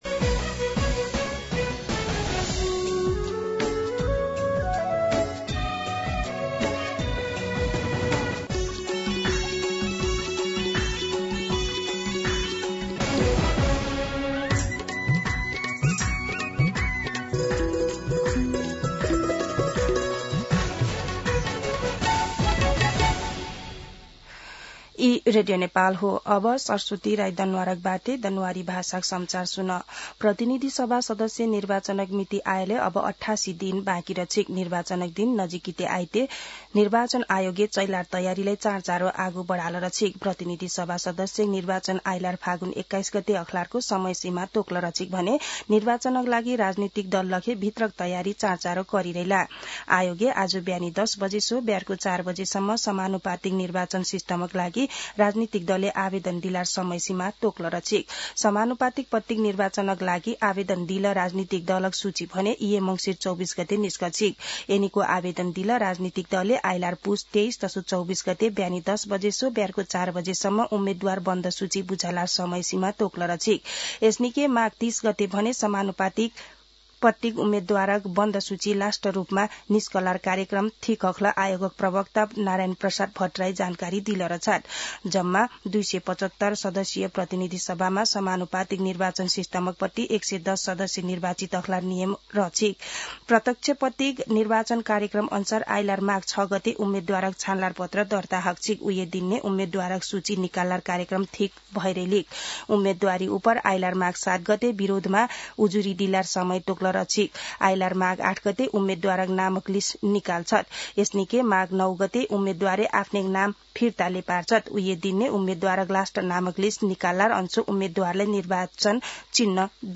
दनुवार भाषामा समाचार : २१ मंसिर , २०८२
Danuwar-News-8-21.mp3